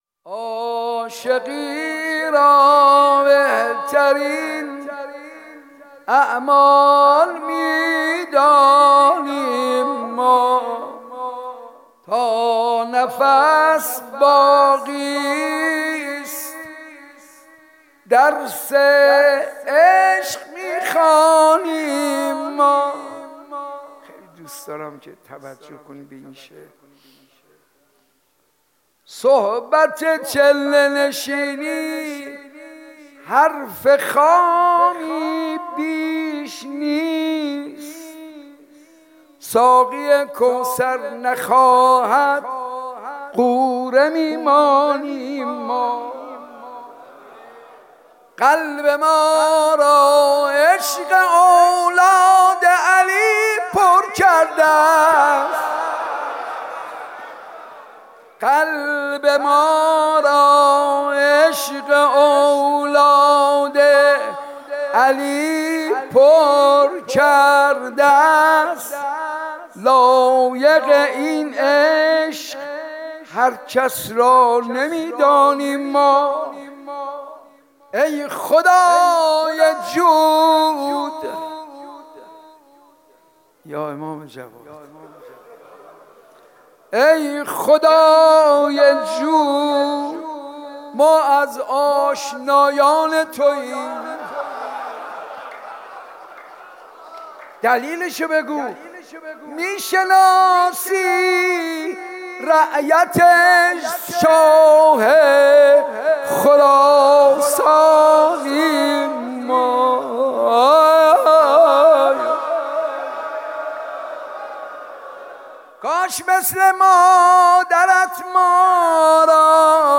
مداحی به سبک مناجات اجرا شده است.